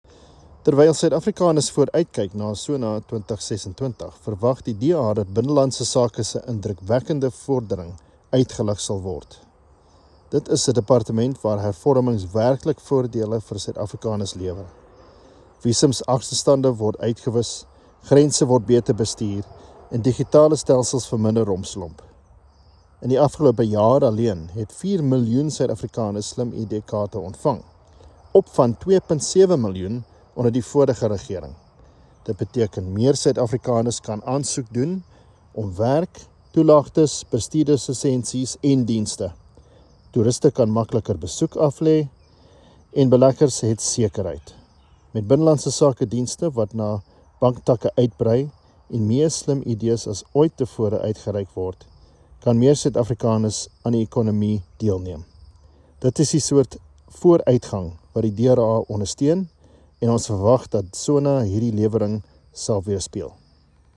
Afrikaans soundbites by Adrian Roos MP.